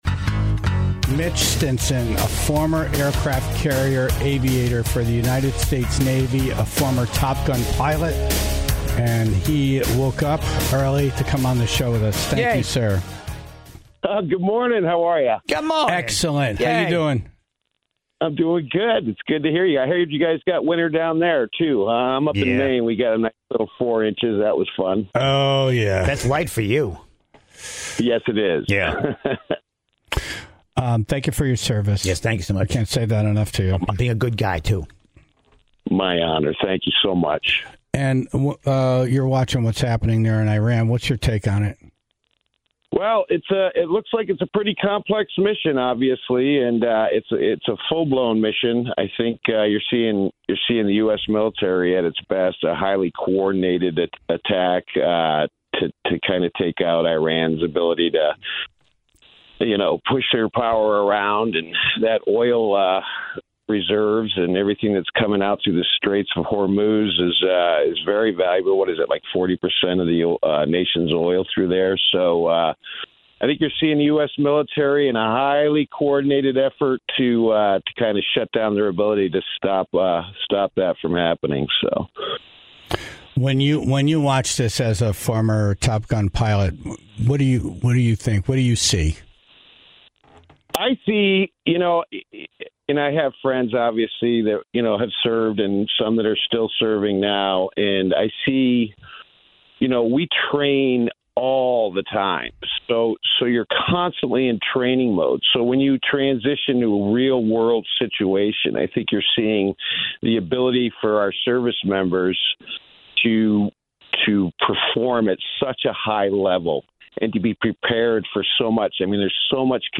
spoke with a former aircraft carrier aviator